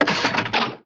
LeverCartoon HB01_67_1.wav